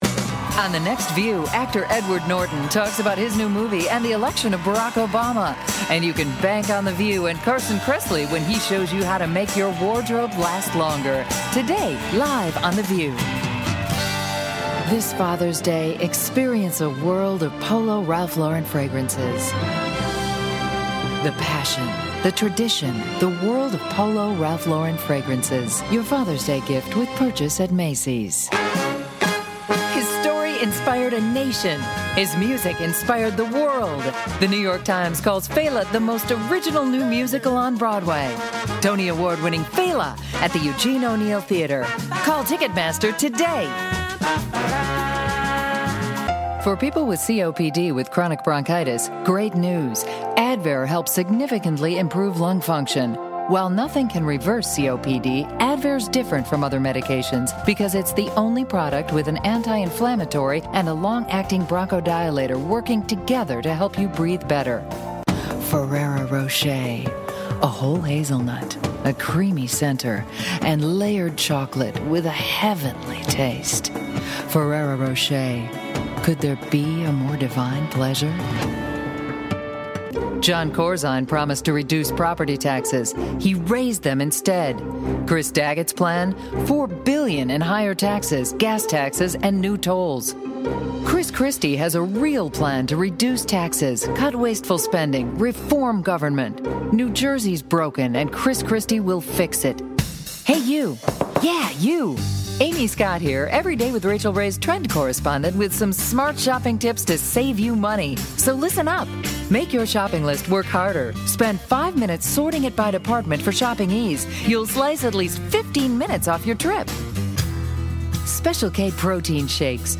She has a warm, rich voice... which can be authoritative, or professional for narrations or friendly and fun for commercials.
Voice Overs